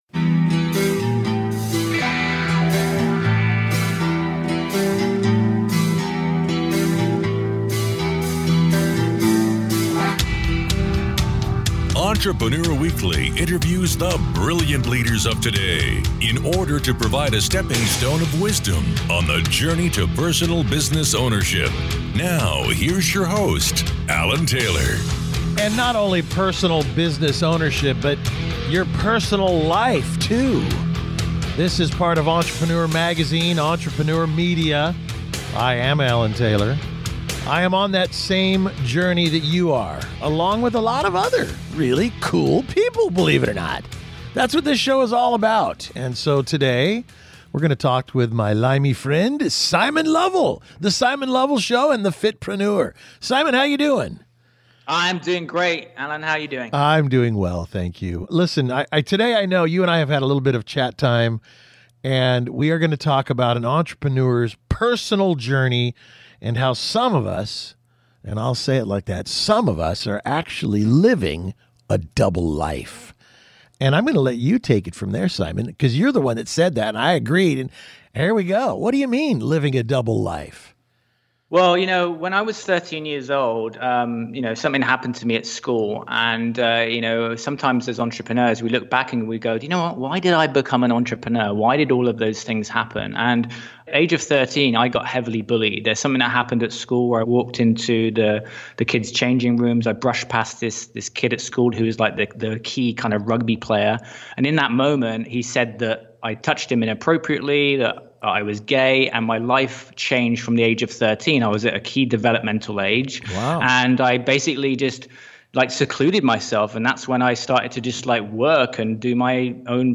In a candid interview